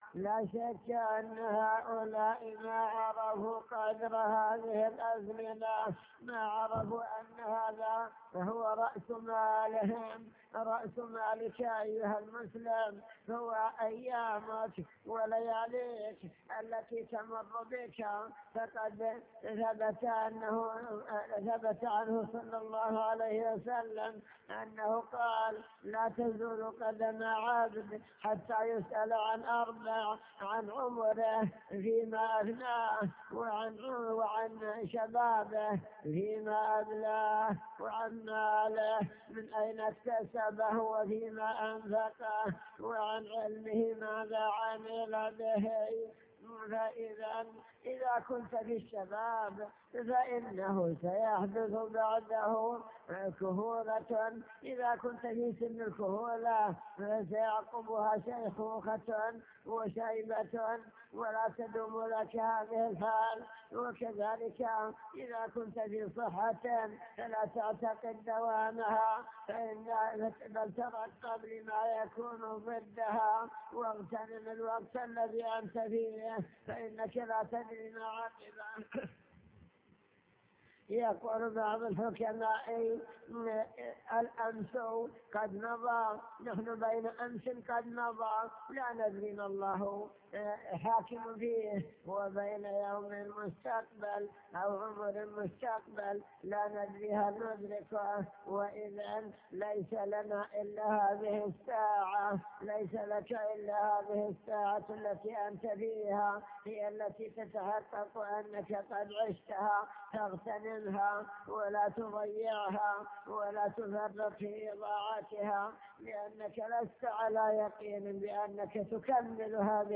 المكتبة الصوتية  تسجيلات - محاضرات ودروس  محاضرة بعنوان المسلم بين عام مضى وعام حل